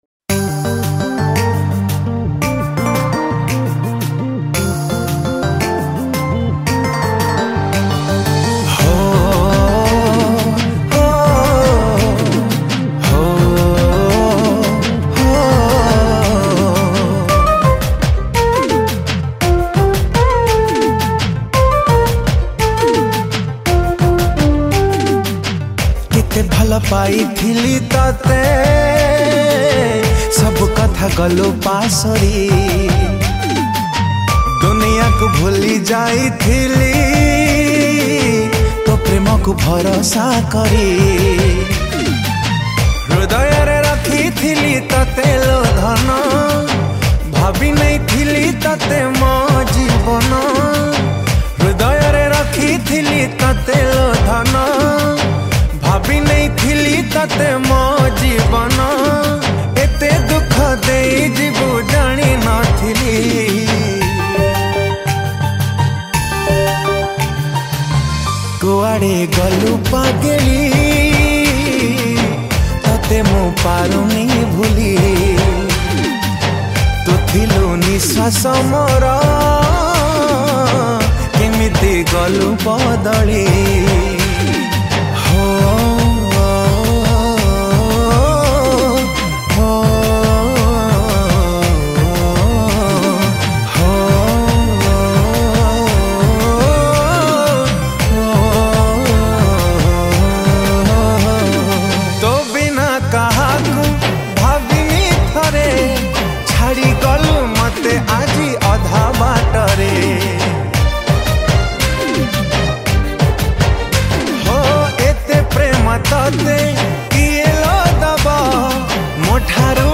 Studio Version